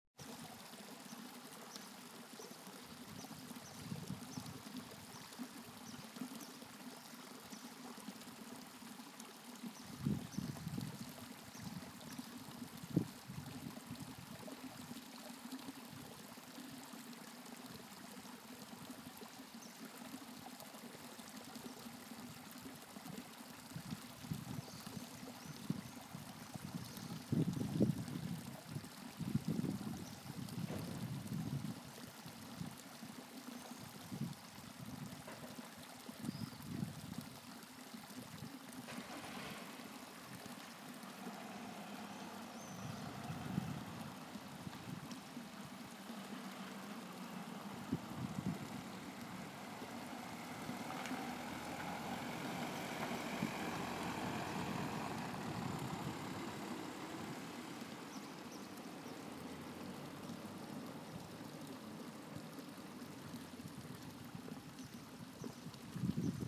typical sound